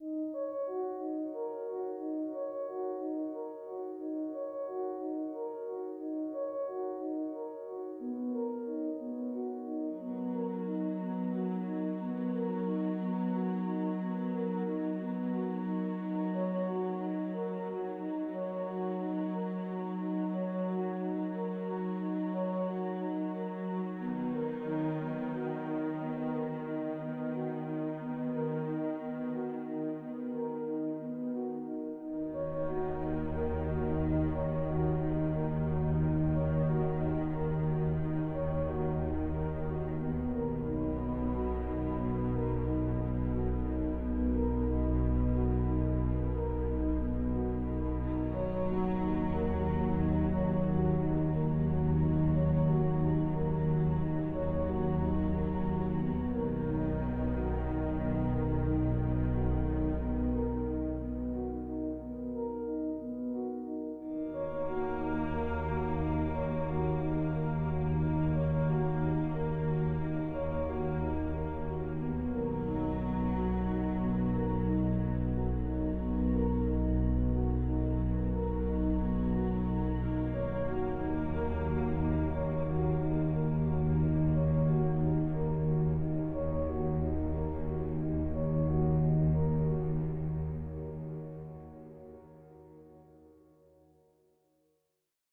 Added Ambient music pack. 2024-04-14 17:36:33 -04:00 26 MiB Raw Permalink History
Ambient Nothingness Intensity 2.wav